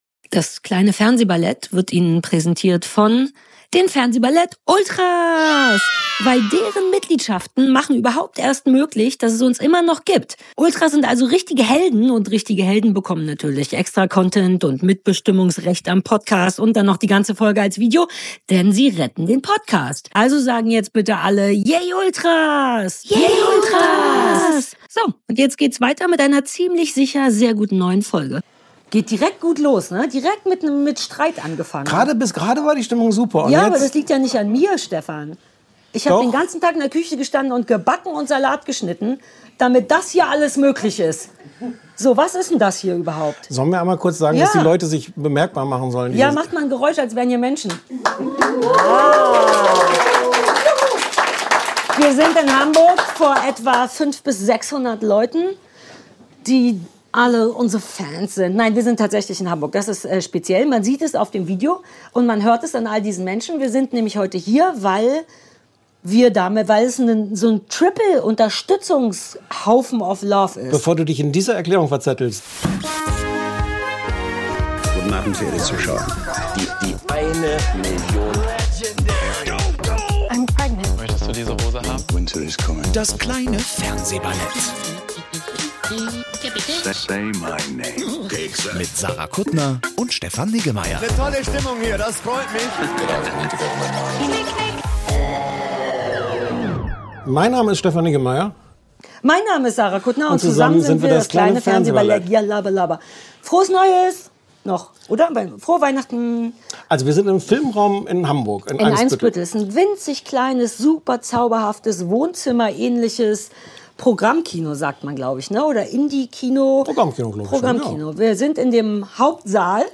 Beschreibung vor 3 Monaten Diese Folge haben wir in Hamburg vor dem besten Publikum der Welt aufgenommen: eine kleine, tapfere Schar von Ultras, die es (wie wir) trotz widriger Wetterbedingungen ins filmRaum-Kino nach Eimsbüttel geschafft haben!